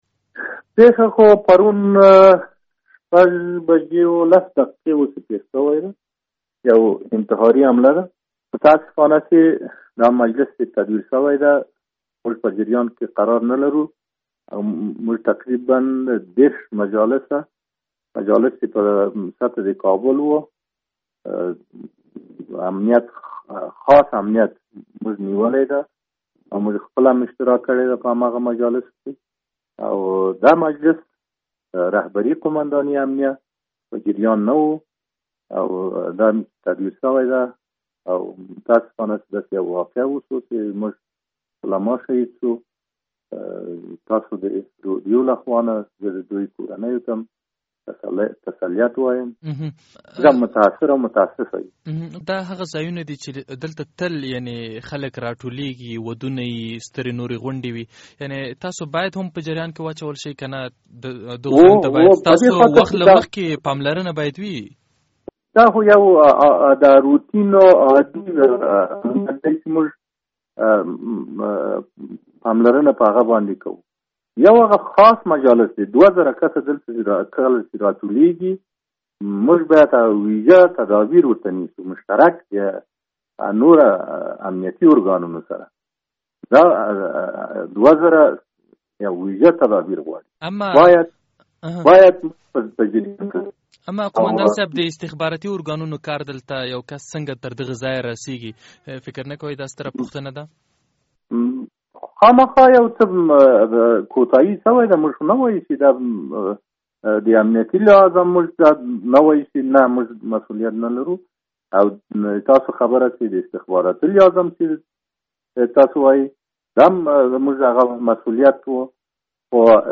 مرکه
قوماندان سید محمد روشندل له ازادي راډیو سره په مرکه کې د پېښې په وړاندې استخباراتي ناکامي مني، خو وايي هغه کسان به جزا وویني چې په خپله دنده کې پاتې راغلي دي.